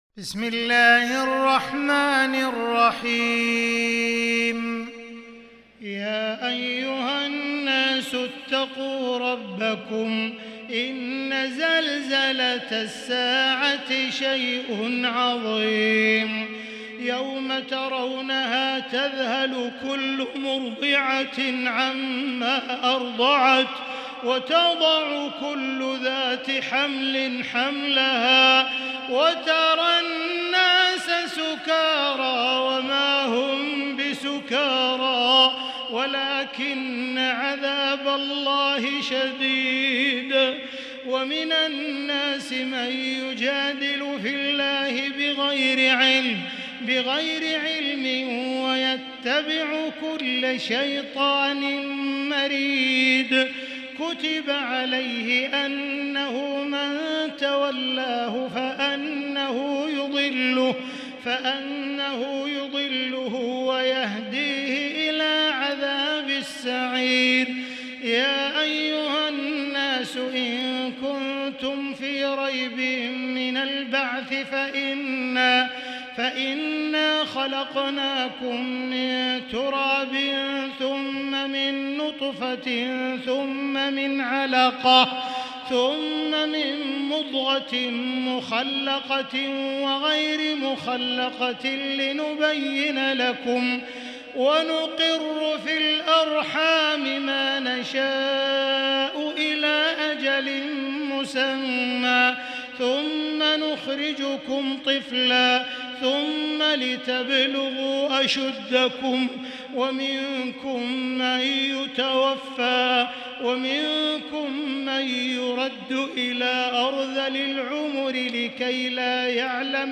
المكان: المسجد الحرام الشيخ: معالي الشيخ أ.د. بندر بليلة معالي الشيخ أ.د. بندر بليلة الحج